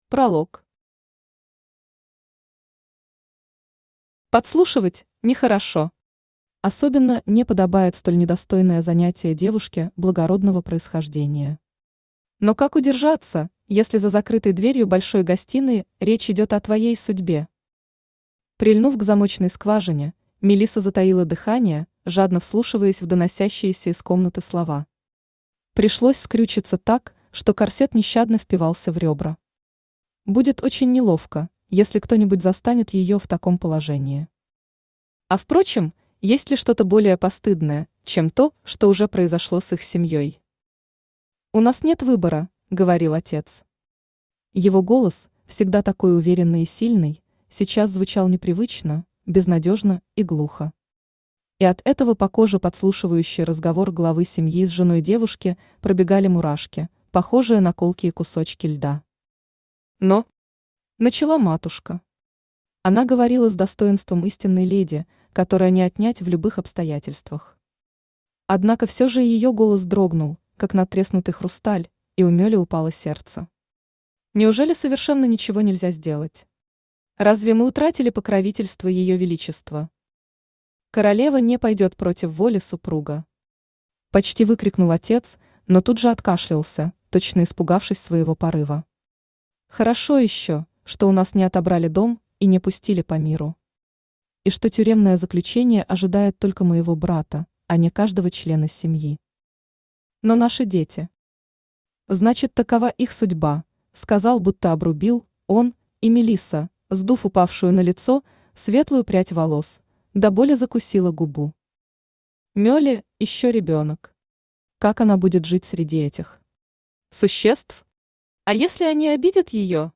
Aудиокнига Моя (чужая) невеста Автор Светлана Казакова Читает аудиокнигу Авточтец ЛитРес.